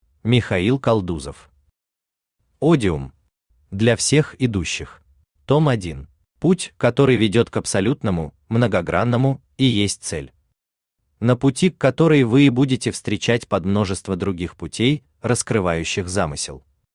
Аудиокнига Одиум. Для всех идущих | Библиотека аудиокниг
Для всех идущих Автор Михаил Константинович Калдузов Читает аудиокнигу Авточтец ЛитРес.